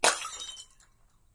描述：1个轻快的啤酒瓶砸，锤子，装满液体，叮当响
标签： 瓶破 瓶粉碎 填充液体的
声道立体声